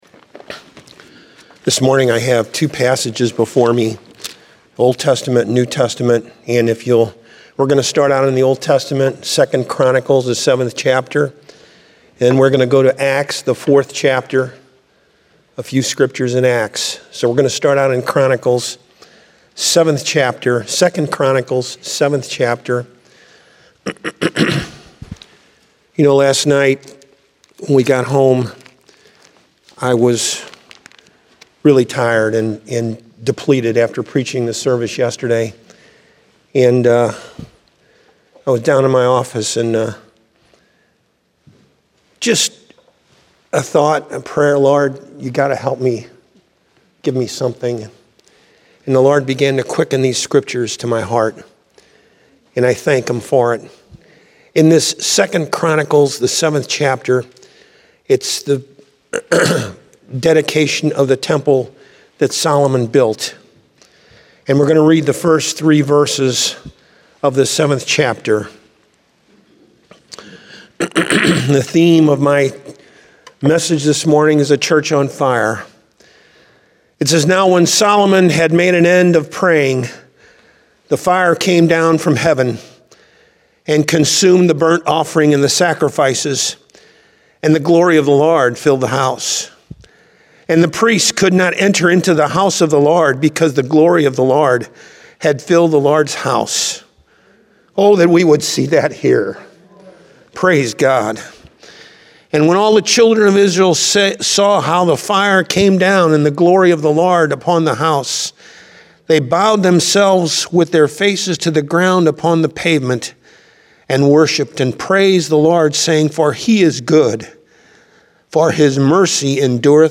Download Sermon Audio File Evangelical Full Gospel Assembly